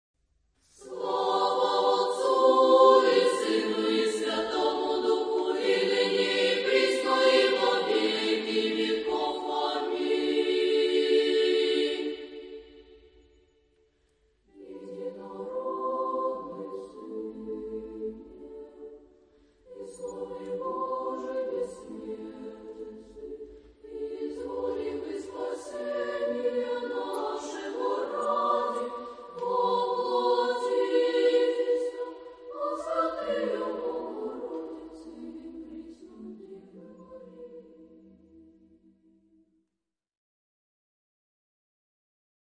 SATB (4 voices mixed) ; Choral score.
Genre-Style-Form: Sacred ; Orthodox liturgical hymn ; Orthodox song Mood of the piece: solemn ; majestic ; prayerful Type of Choir: SATB (4 mixed voices )
Tonality: A minor